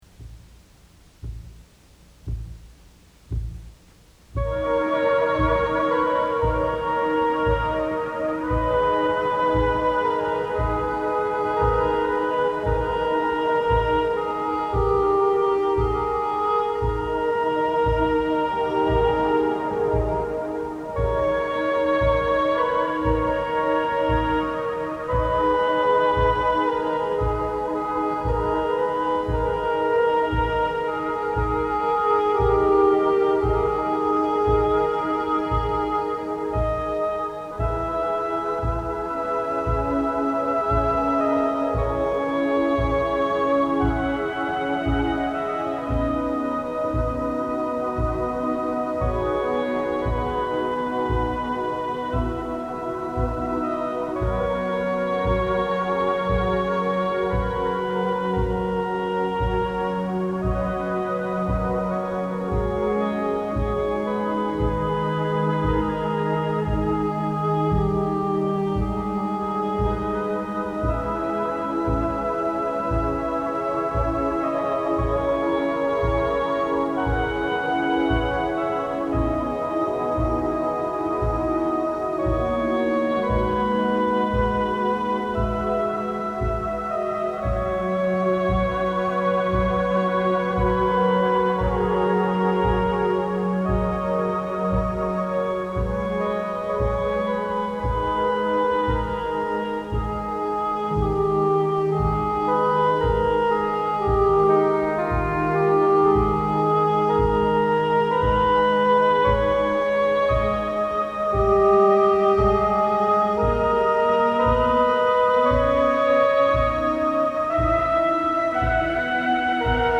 ατμοσφαιρική μουσική